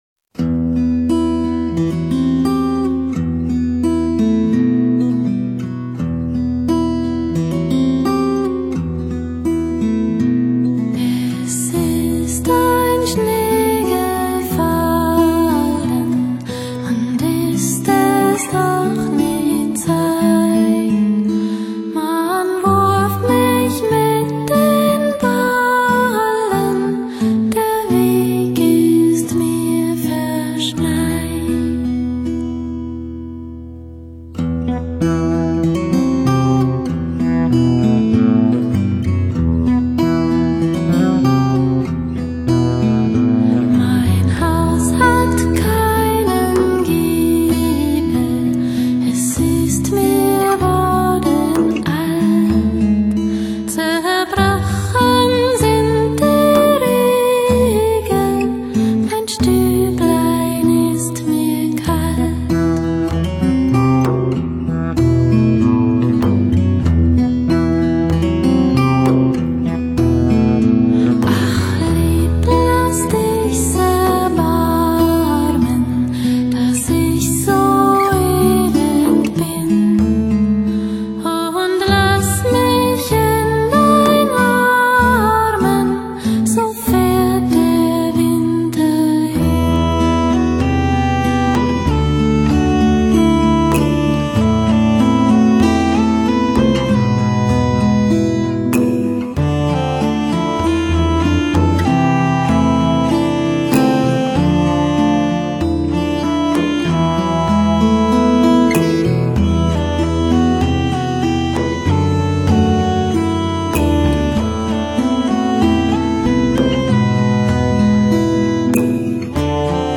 女声清冽，旋律淡雅， 歌曲无形中透露出神奇的魔力慢慢包围你的灵魂， 如同古代海洋中美人鱼用鬼魅的歌声吸引航海的水手。